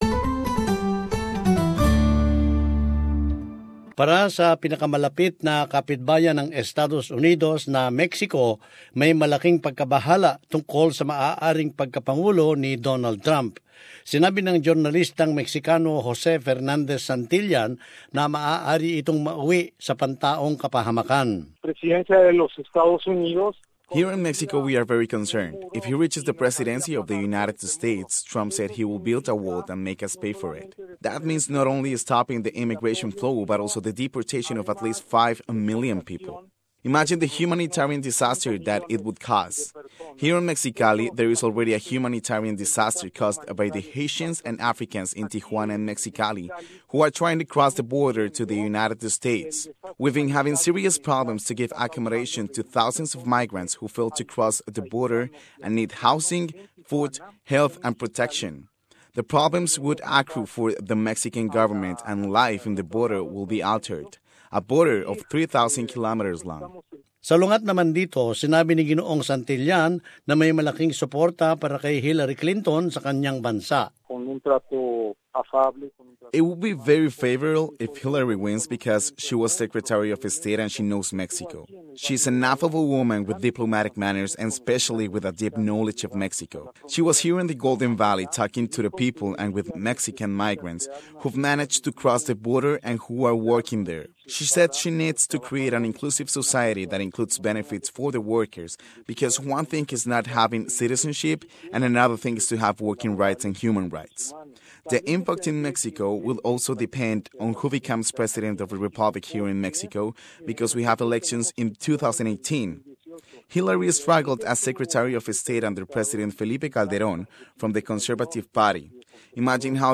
compiled from interviews by SBS Radio's language programs.